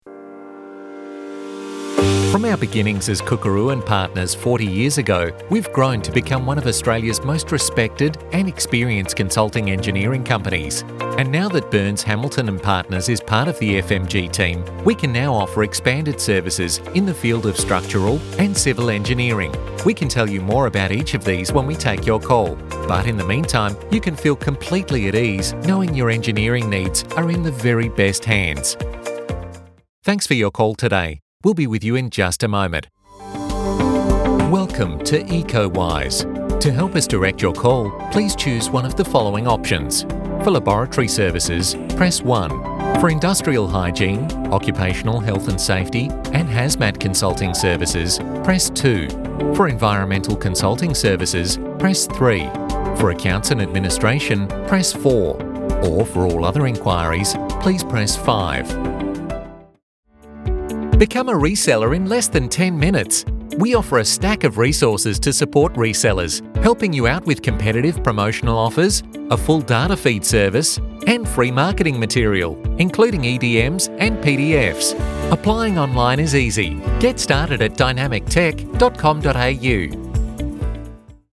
Engels (Australië)
Diep, Veelzijdig, Warm, Zakelijk
Telefonie